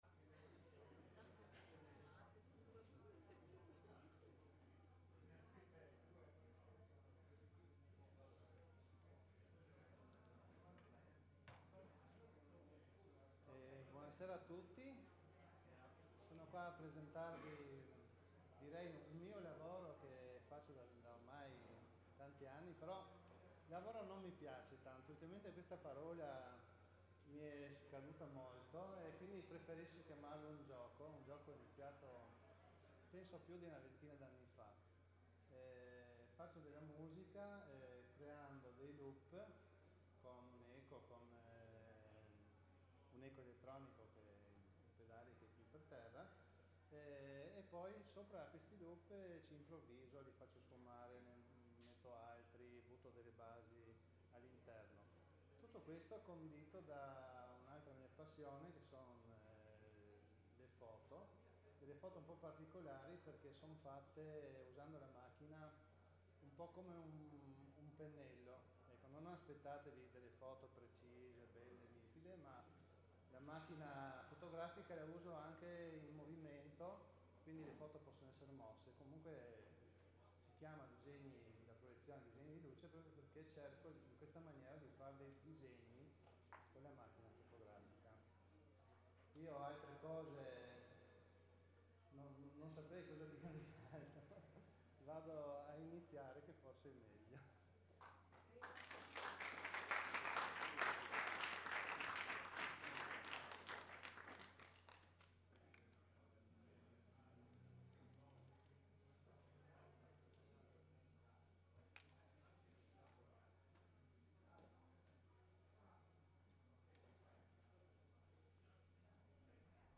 musica elettronico - acustica minimalista eseguita dal vivo